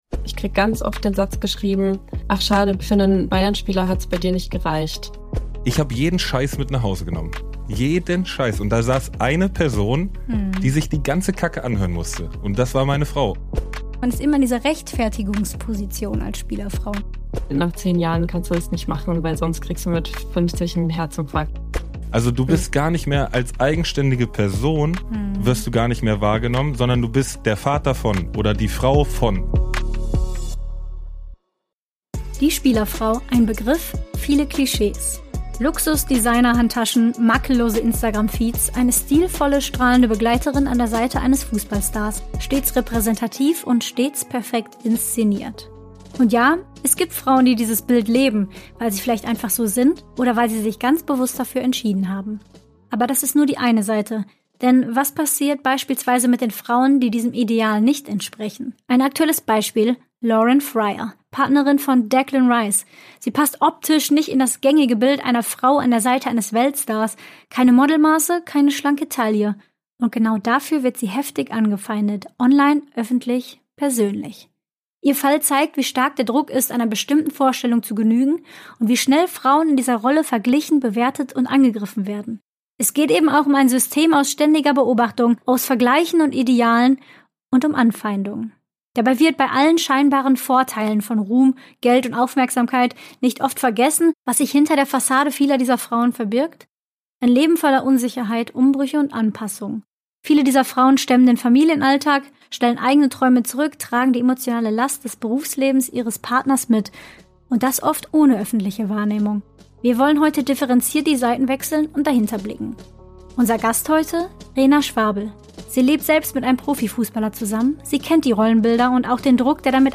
Studio & Postproduktion: Foundation Room Studio